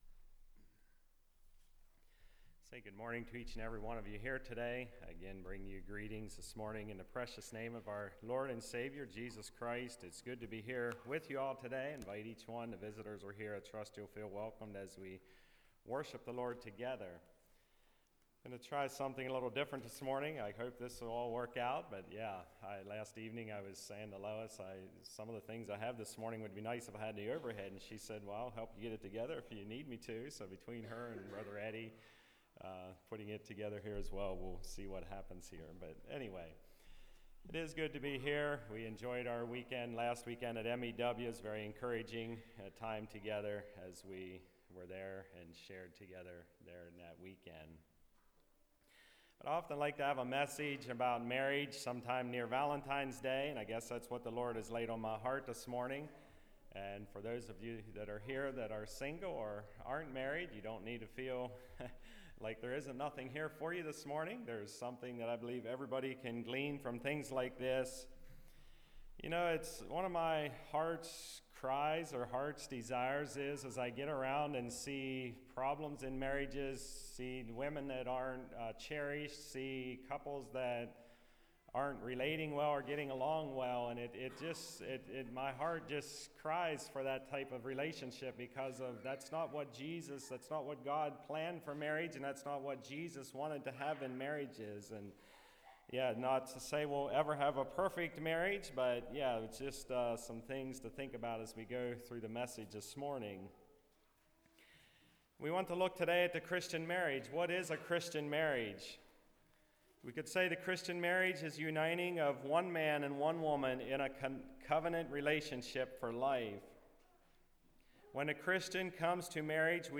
Service Type: Message